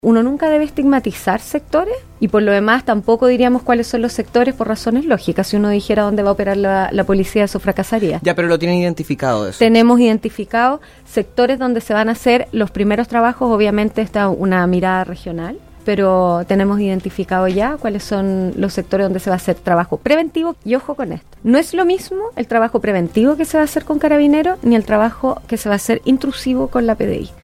En entrevista con Radio Bío Bío, la autoridad nacional dijo que se están adoptando medidas adoptadas en conjunto con la Fiscalía para evitar una escalada en la violencia en la zona.